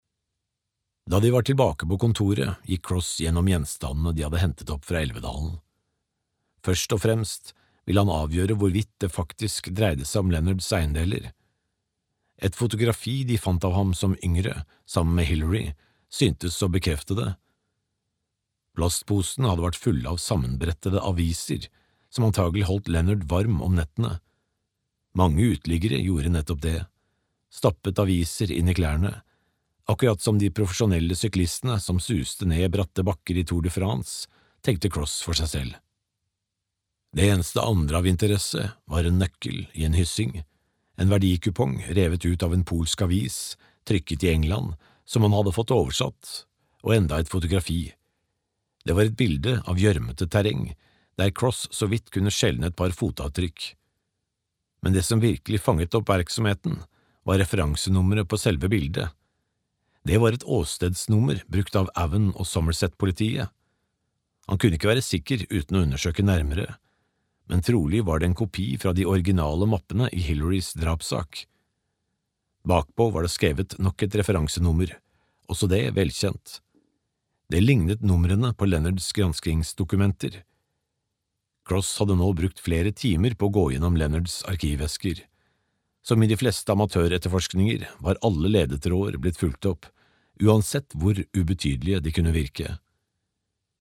Tannlegen (lydbok) av Tim Sullivan